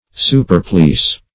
Superplease \Su`per*please"\, v. t. To please exceedingly.